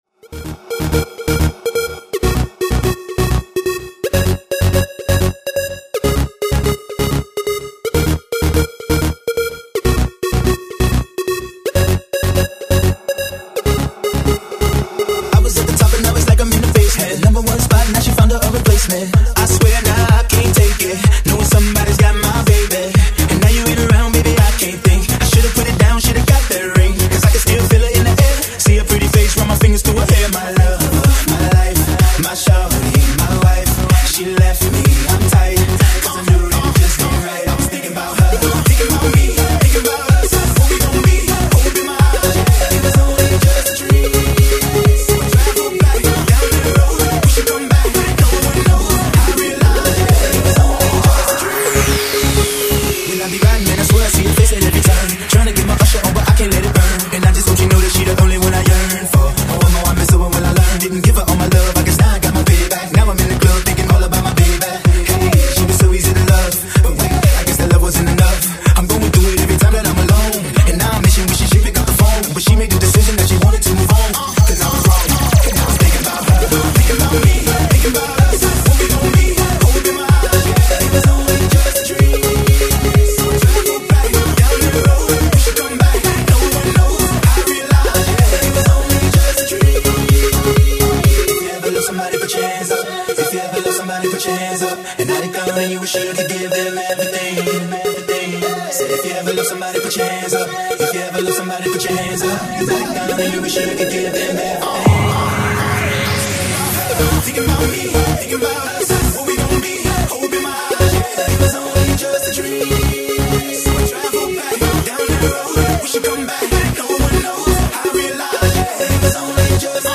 Жанр: Dance music